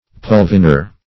Search Result for " pulvinar" : The Collaborative International Dictionary of English v.0.48: Pulvinar \Pul*vi"nar\, n. [L., a cushion.]